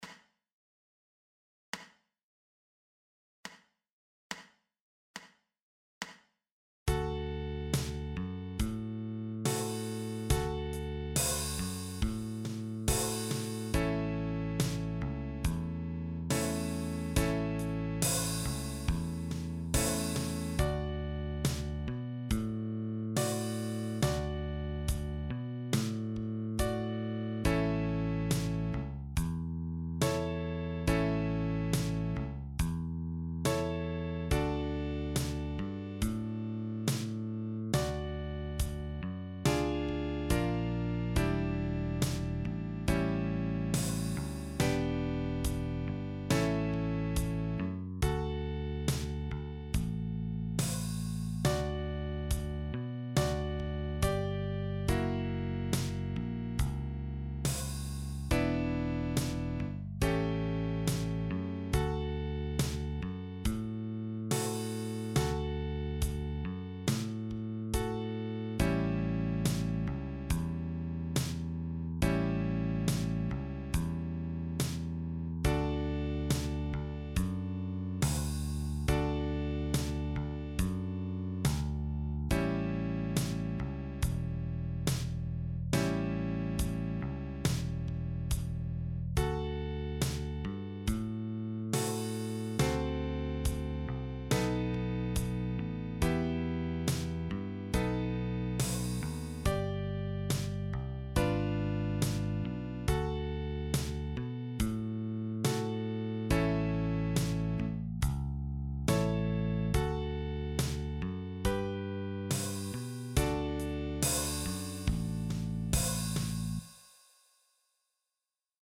Liederbuch für die chromatische Mundharmonika.
Soundbeispiel – Melodie & Band sowie Band alleine: